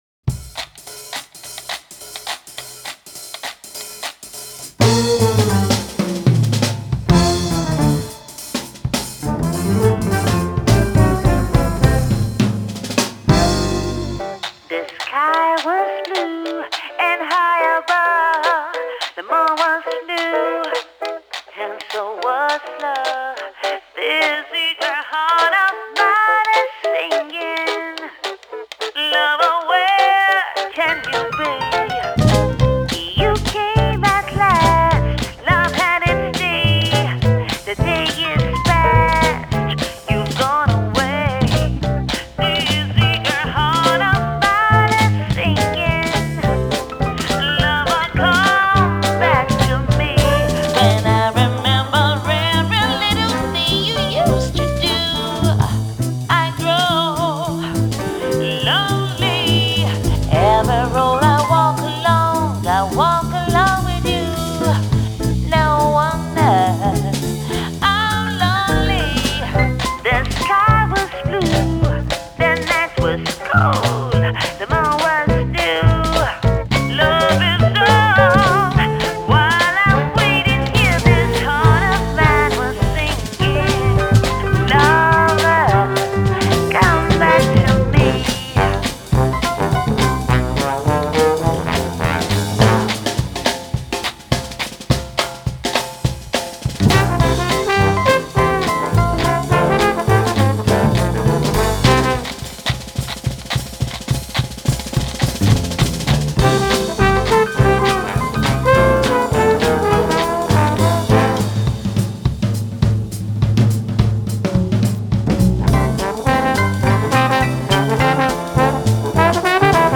Genre: Blues, Vocals